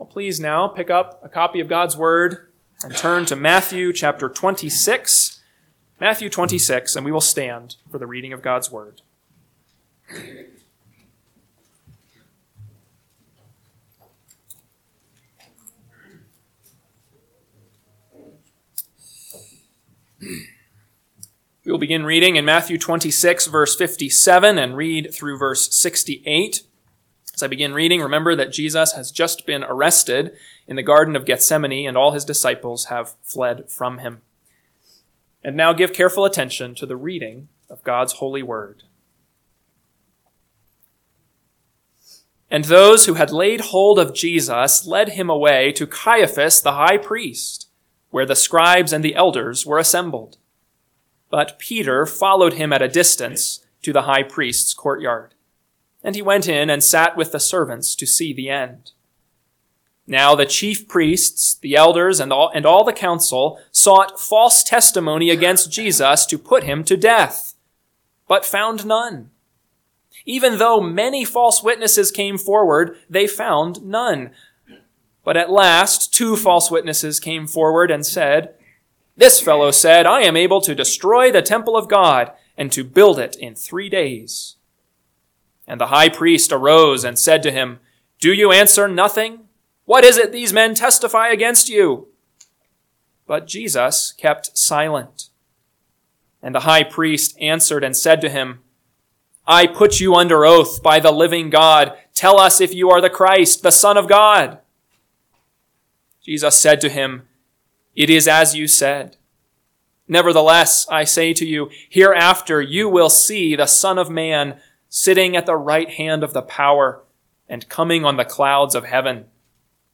AM Sermon – 3/23/2025 – Matthew 26:57-68 – Northwoods Sermons